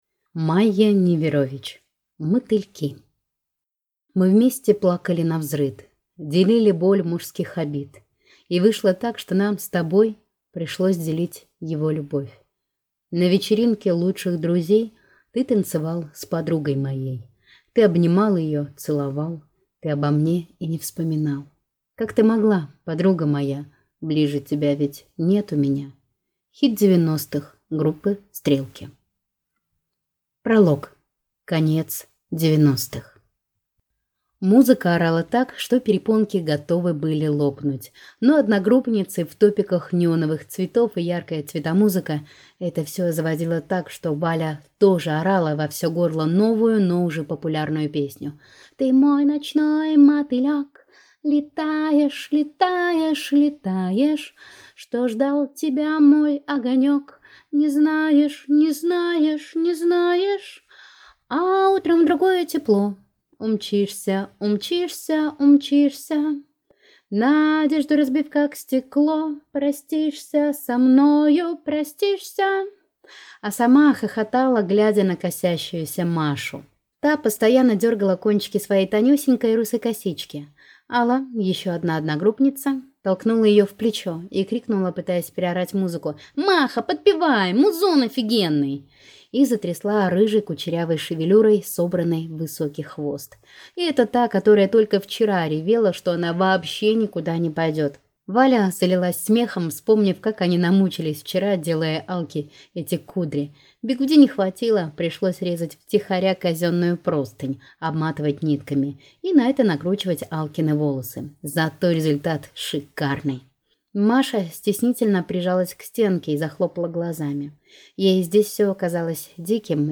Аудиокнига Мотыльки | Библиотека аудиокниг